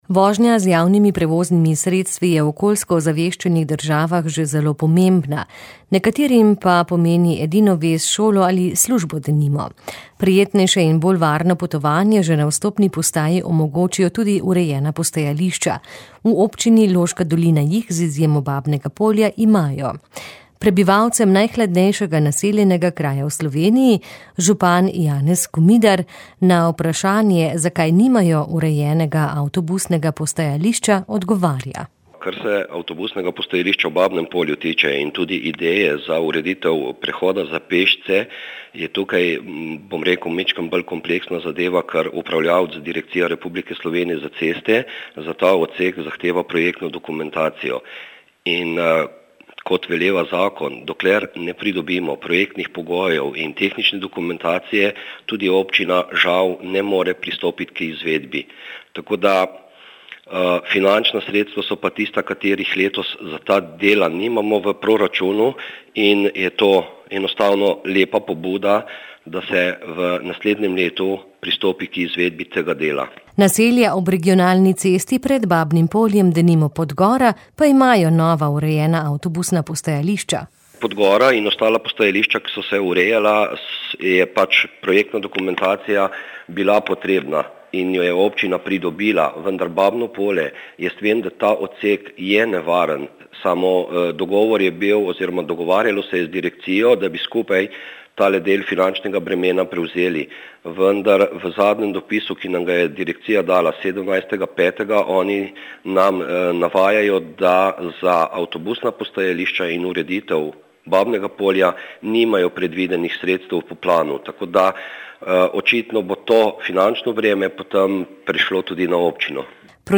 V Babnem Polju še nimajo urejenega avtobusnega postajališča. Župan Občine Loška dolina Janez Komidar je povedal, da bodo jeseni izdelali osnutek prihodnjega proračuna in v njem opredelili sredstva za projektno dokumentacijo in ureditev avtobusnega postajališča tudi v tem naselju.